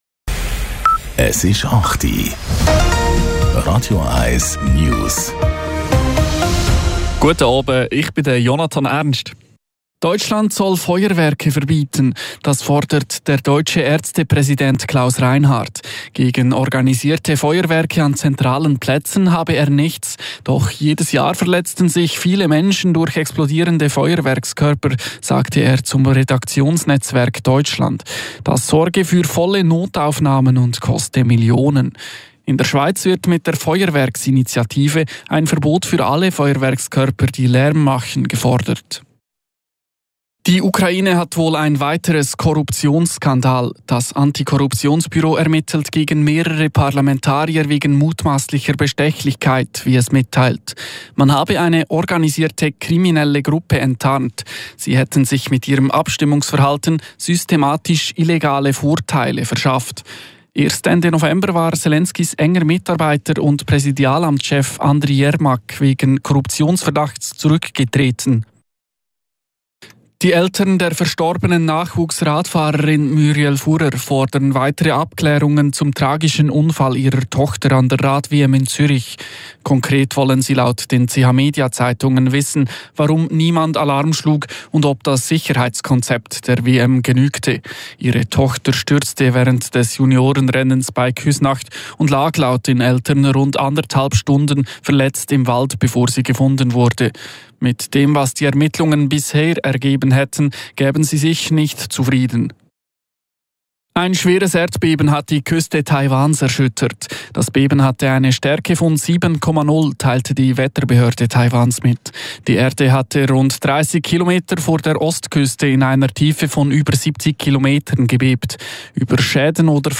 Die aktuellsten News von Radio 1 - kompakt, aktuell und auf den Punkt gebracht.